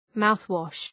Shkrimi fonetik {‘maʋɵwɒʃ}